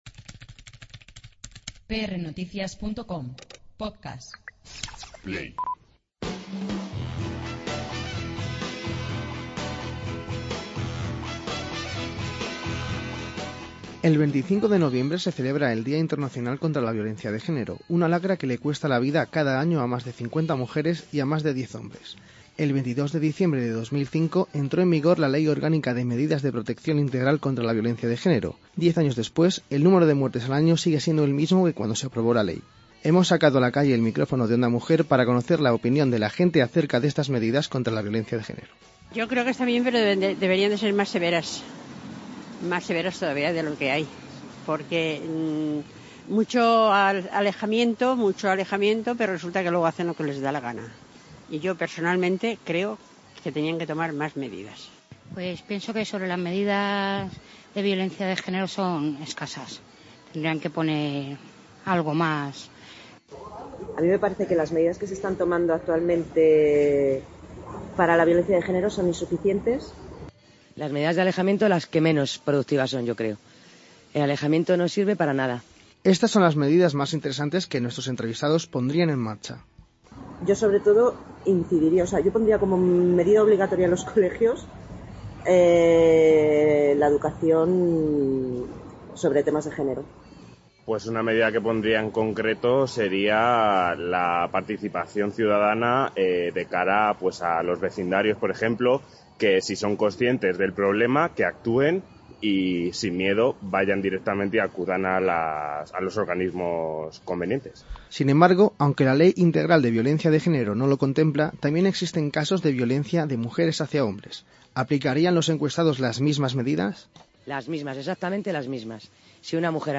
Hemos sacado a la calle el micrófono de ondamujer para conocer la opinión de la gente acerca de estas medidas contra la violencia de género. Todos los encuestados han señalado que la ley contra este tipo de violencia es “insuficiente” e “ineficaz” y que sería importante introducir en la educación de los más jóvenes este tema para prevenirlo desde tempranas edades. El punto en el que más en desacuerdo están todos es que aplicarían exactamente los mismos castigos en los casos de violencia de género de mujeres a hombres o en parejas homosexuales.